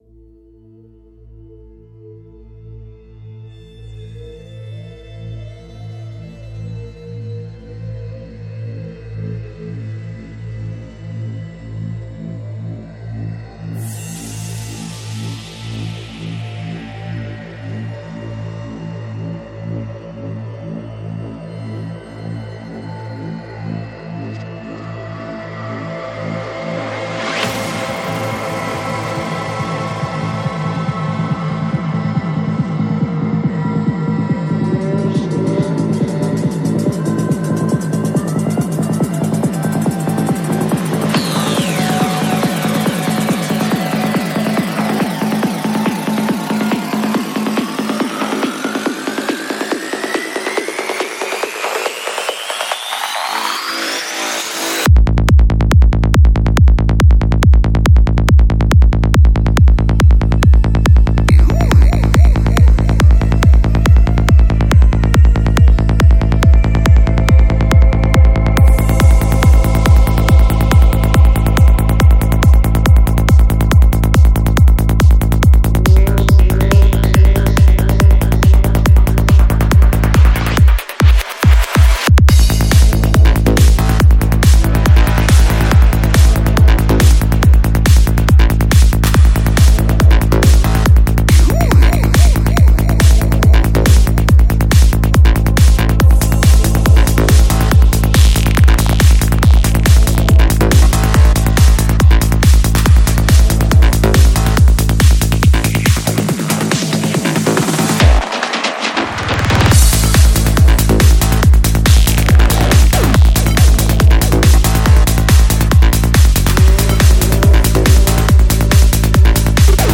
Жанр: Транс
Psy-Trance